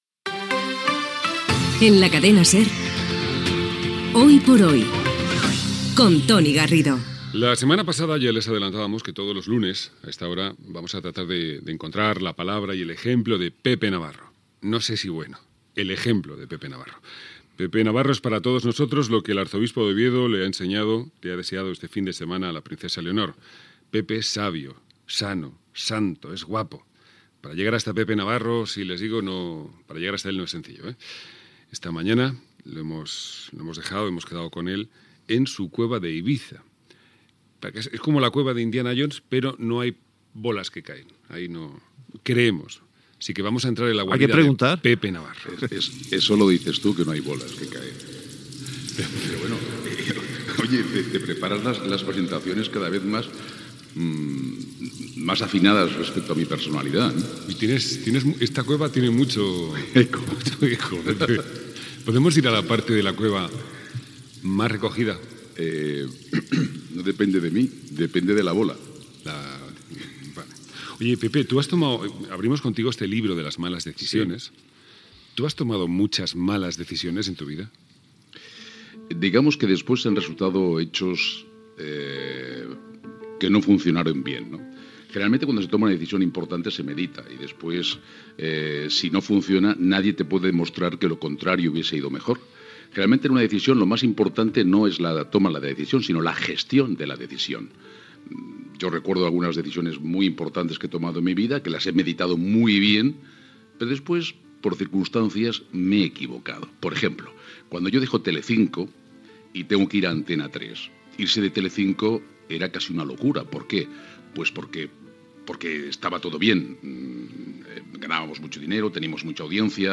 Indicatiu del programa, secció "El libro de las malas decisiones" de Pepe Navarro. Comenta com va anar la decisió que ell va prendre de deixar Telecinco i el fet que Elon Musk fumés un porro en directe en un programa de ràdio dels EE.UU.
Info-entreteniment